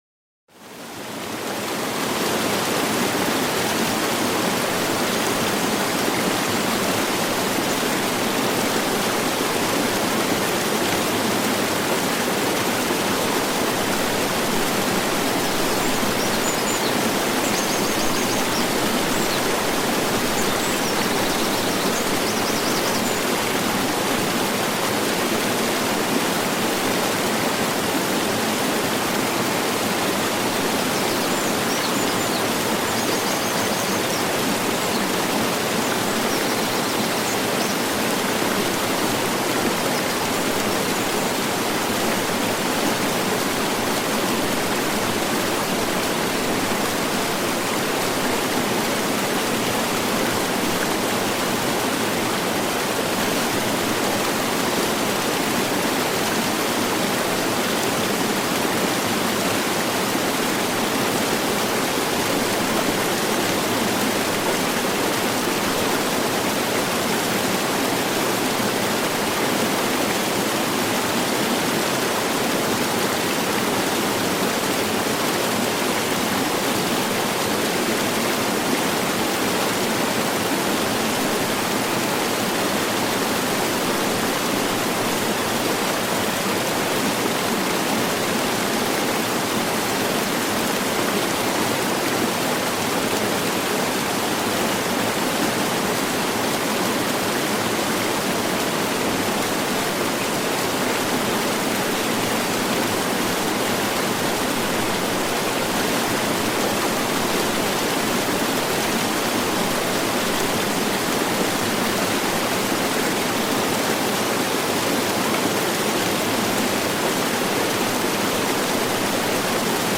TIEFSTE ERHOLUNG: Gebirgsbach-Entspannung als Natur-Geschenk
Naturgeräusche